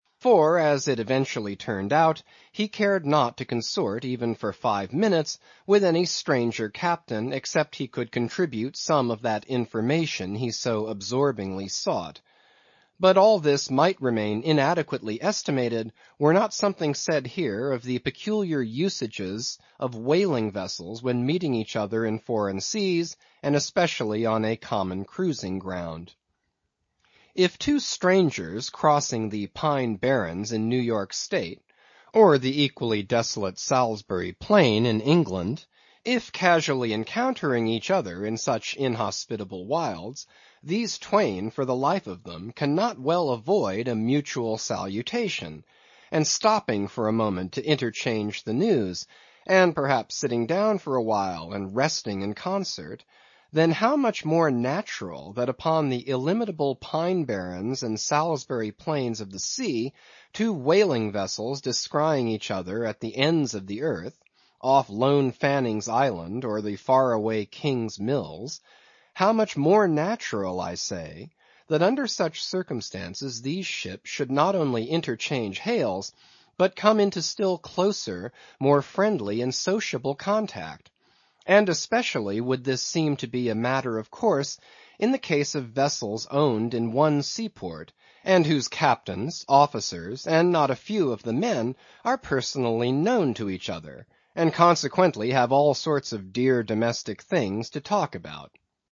英语听书《白鲸记》第519期 听力文件下载—在线英语听力室